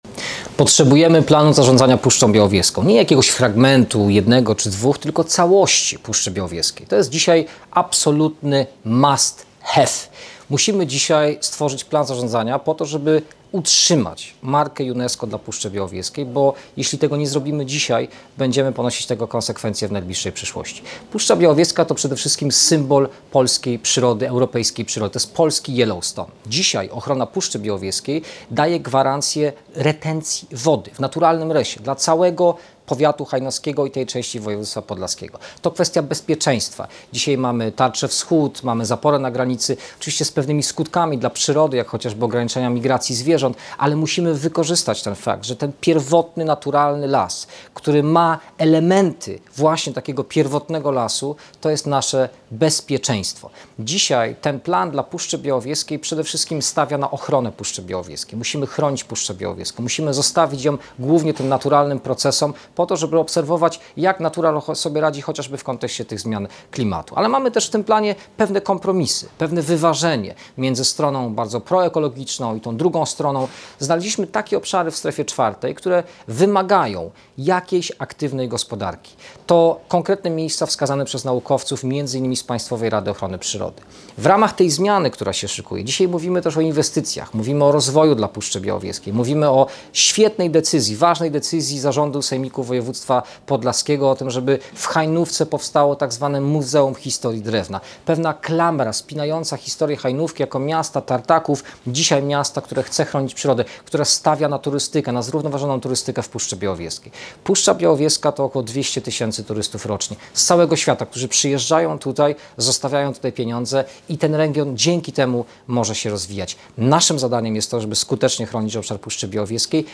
pobierz wypowiedź wiceministra Mikołaja Dorożały: Plan zarządzania Obiektem Światowego Dziedzictwa Białowieża Forest (część polska)